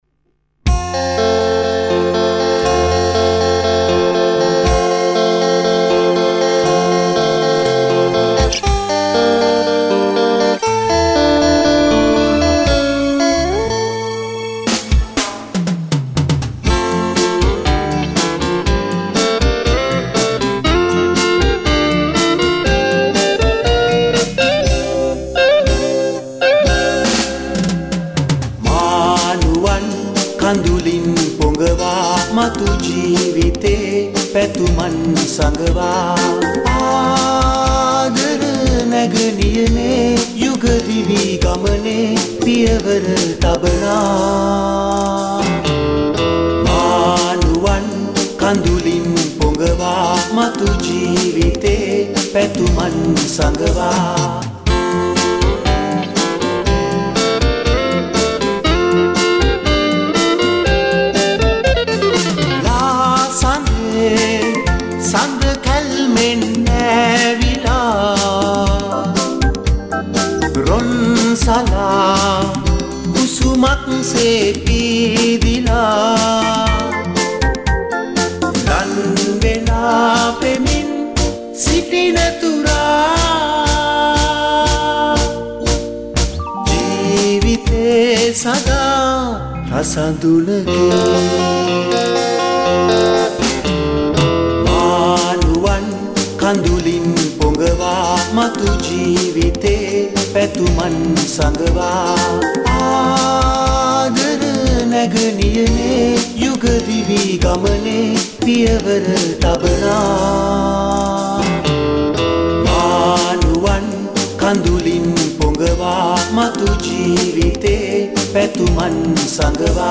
saxapone